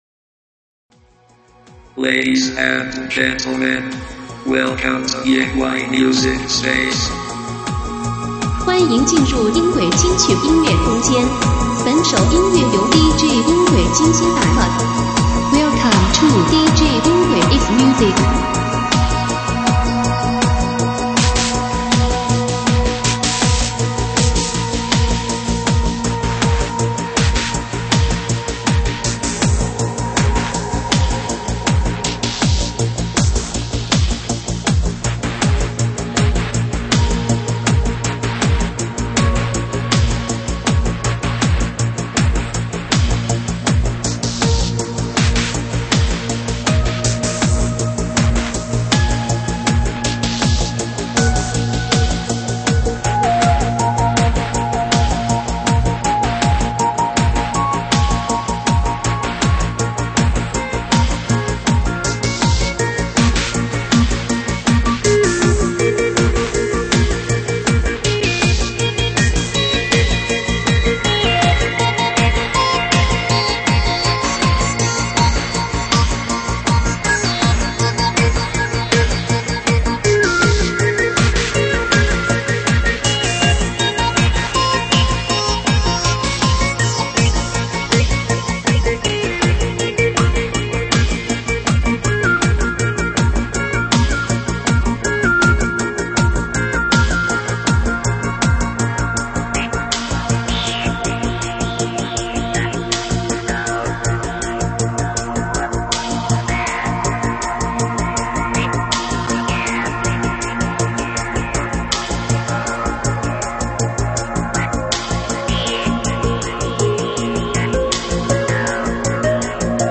[慢摇舞曲]
舞曲类别：慢摇舞曲